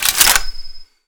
sci-fi_weapon_reload_02.wav